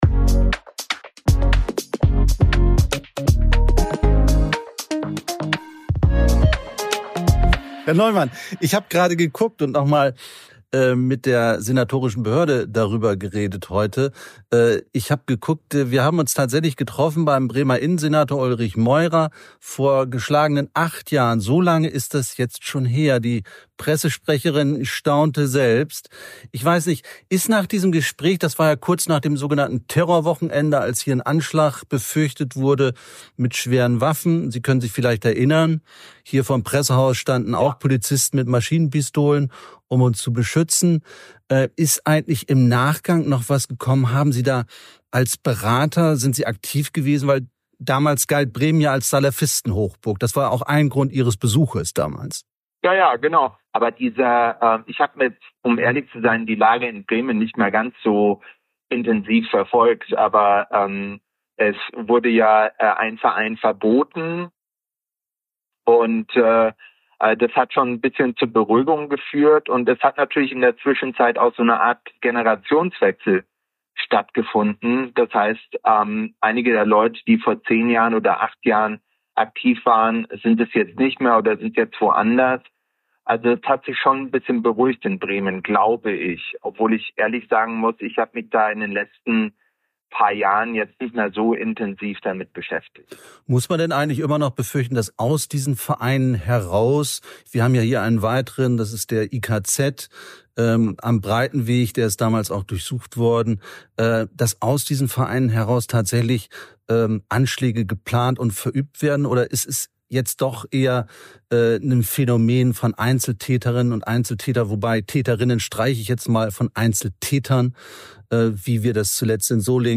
Beschreibung vor 1 Jahr In Folge 25 von "Martini 43" ist Peter Neumann zu Gast.